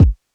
DJP_KICK_ (102).wav